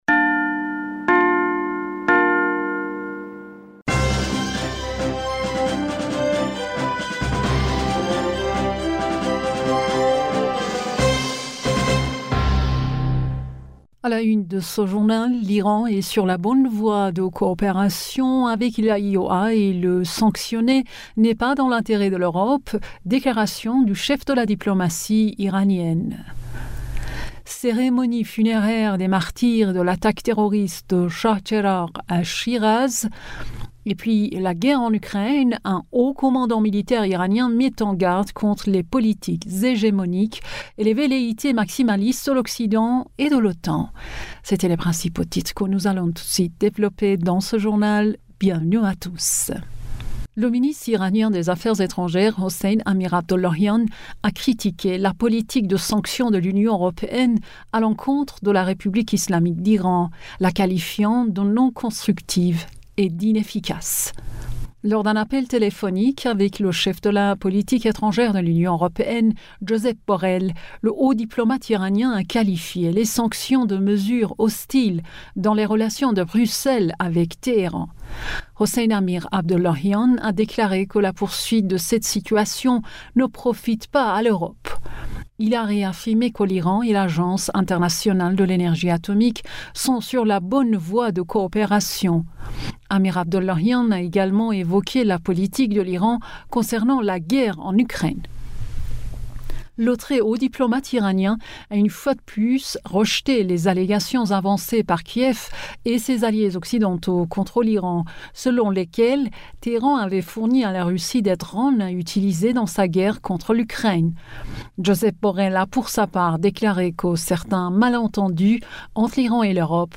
Bulletin d'information du 16 Aout 2023